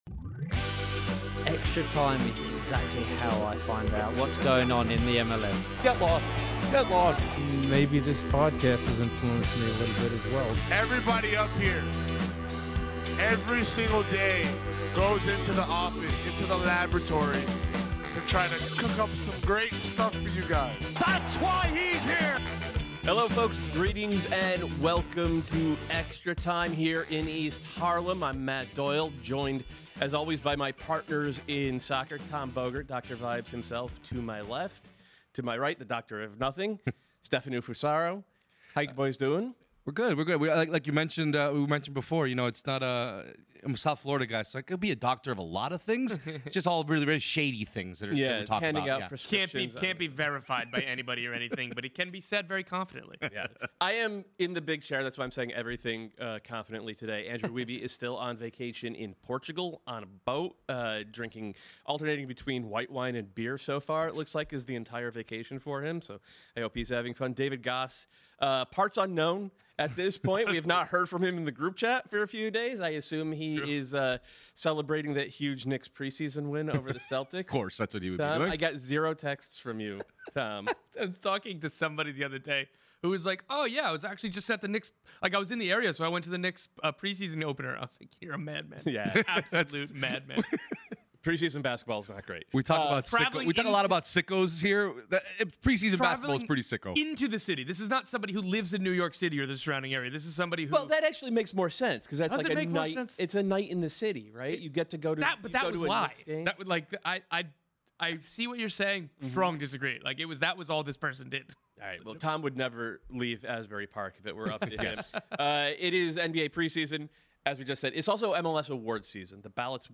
Mailbag show!